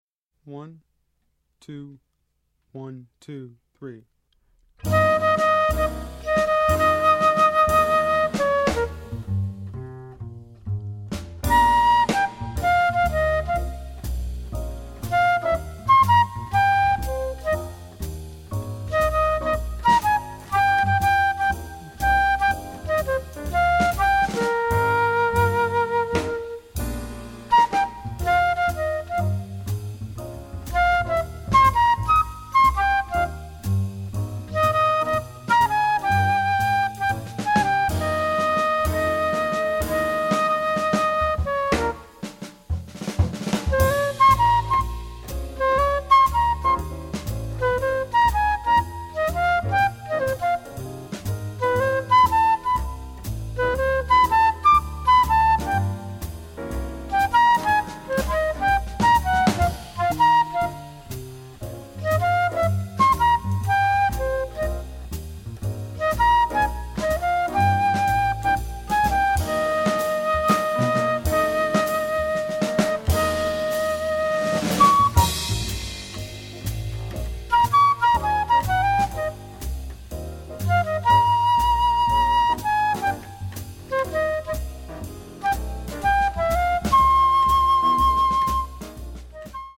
Voicing: Flute